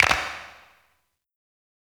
CLAP_KING_SEAT.wav